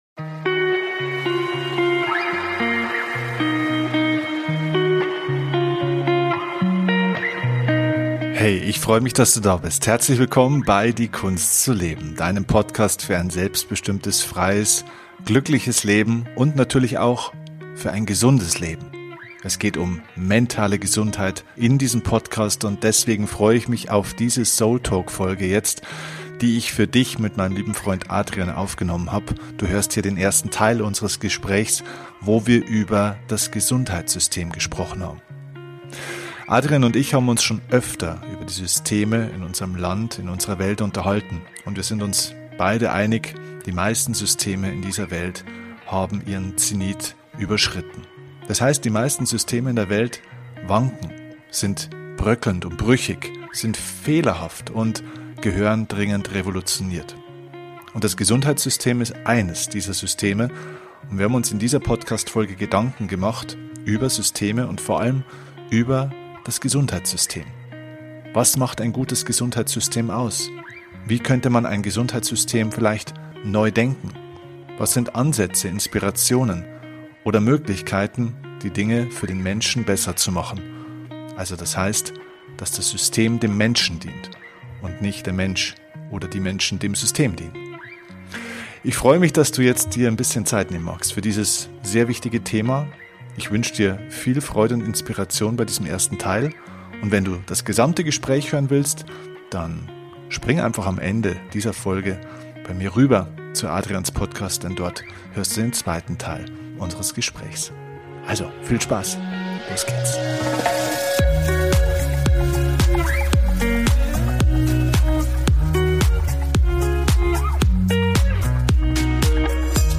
Wie immer: ohne Skript, nur zwei Freunde die reden.